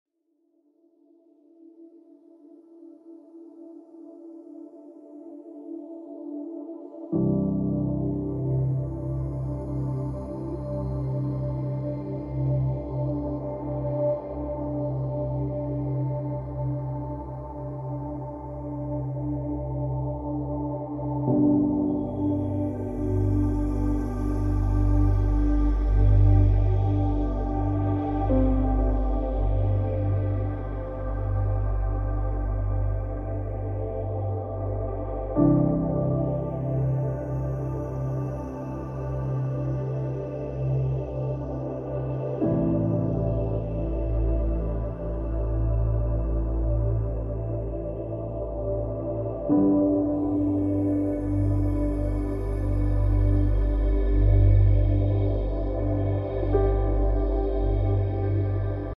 1 minute of silent contemplation.